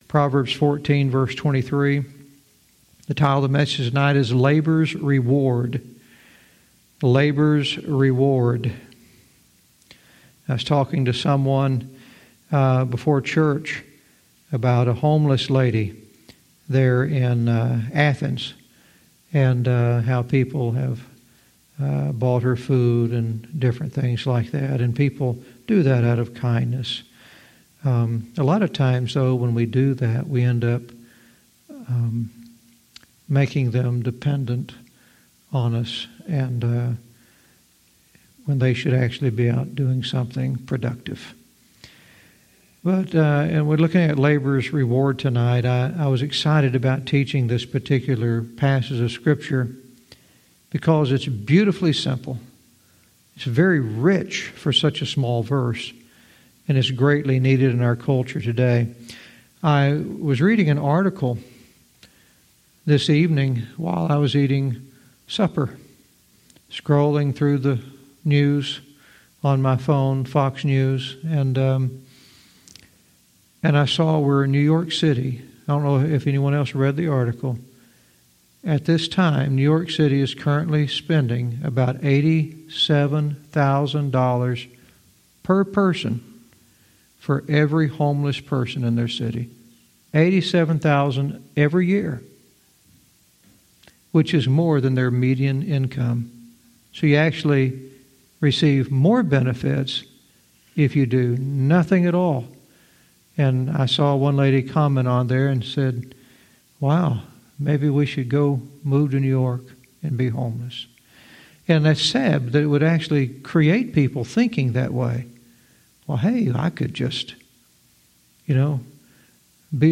Verse by verse teaching - Proverbs 14:23 "Labor's Reward"